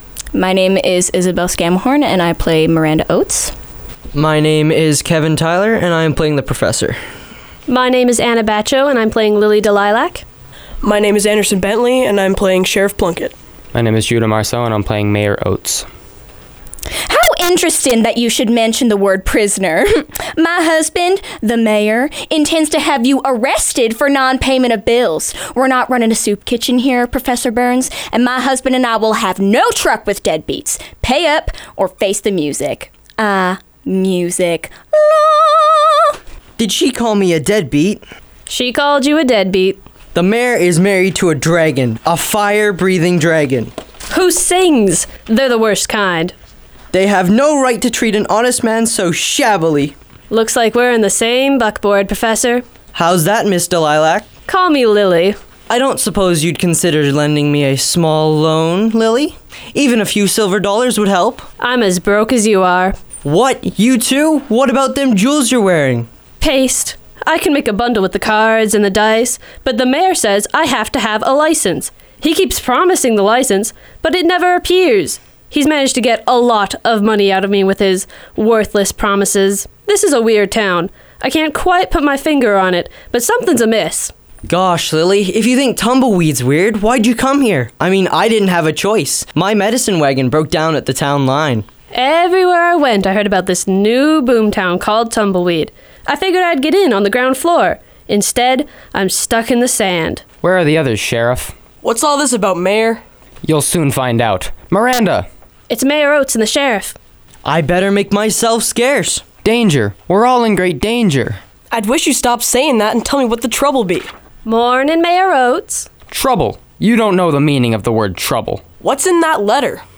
Peace-Players_Performance.wav